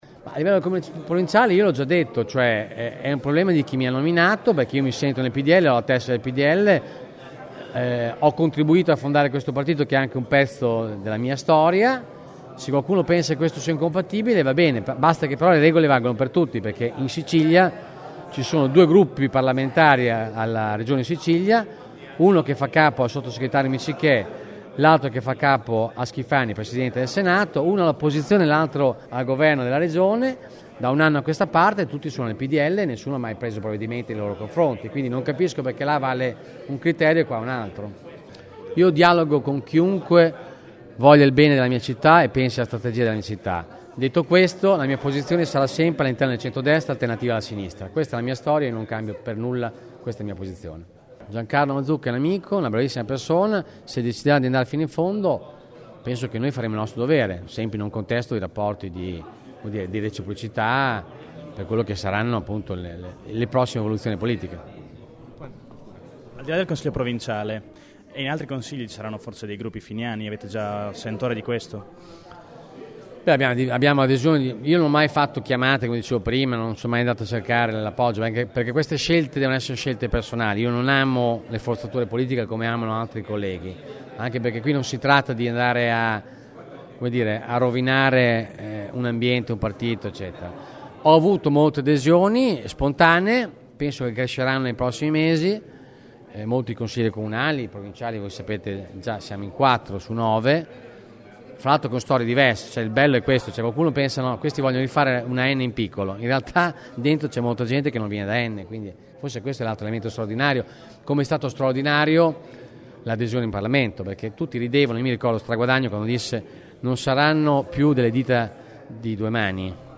31 lug. – Enzo Raisi ha indetto stamane una conferenza stampa, assieme al  nuovo “stato maggiore” finiano, per dire la sua dopo lo strappo tra Berlusconi e Fini.